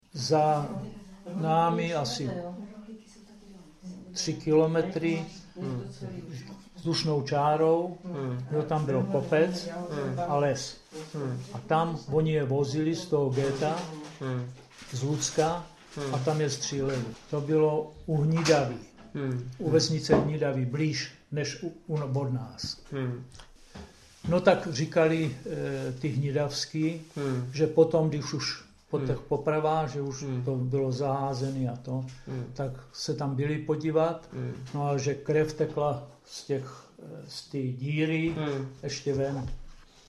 Klip "Osud židů"  z vyprávění pamětníka